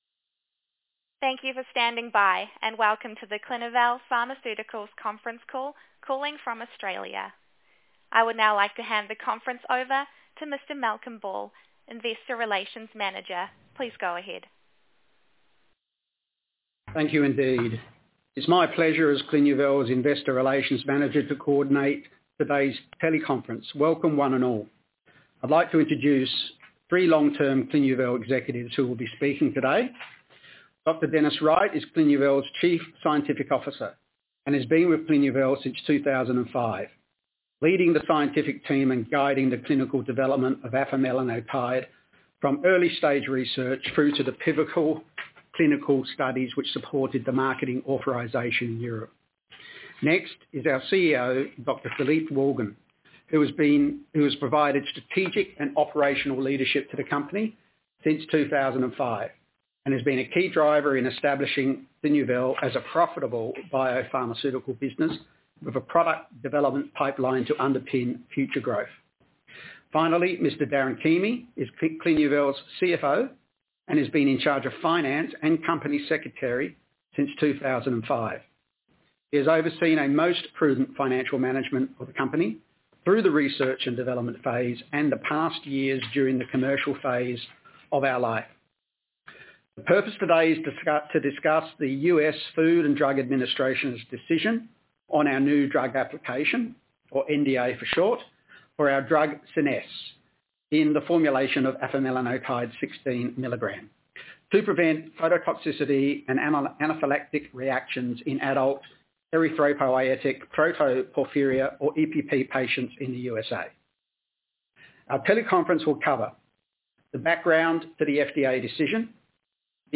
Audio Transcript Investor Teleconference